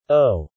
O_male.mp3